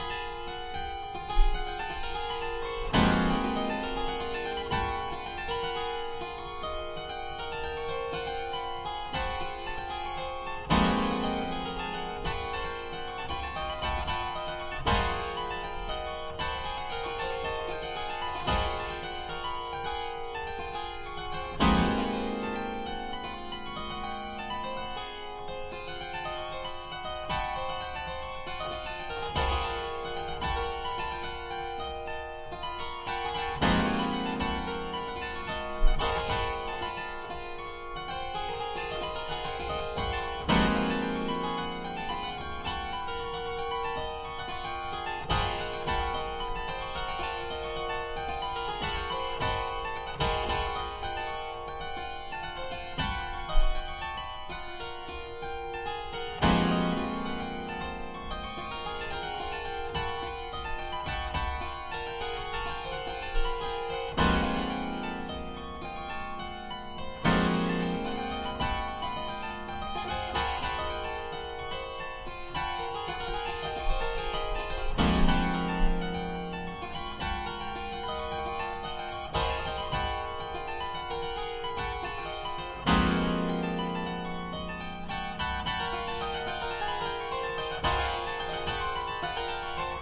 現代音楽